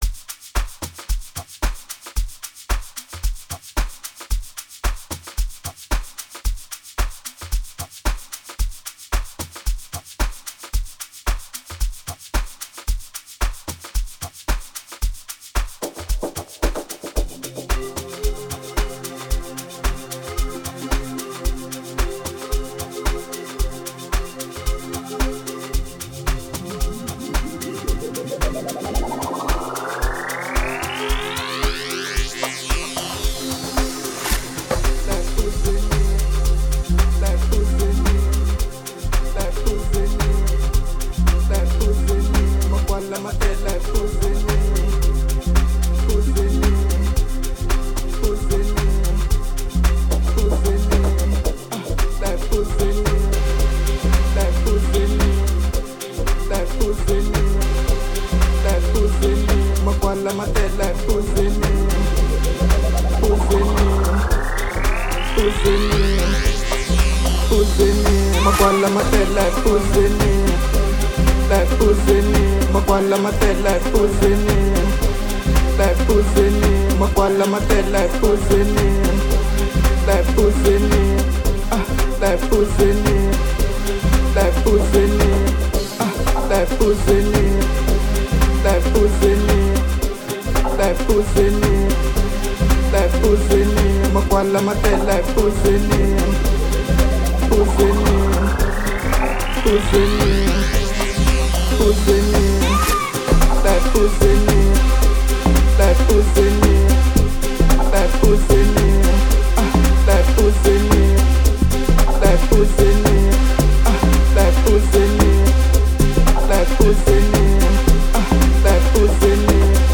05:51 Genre : Amapiano Size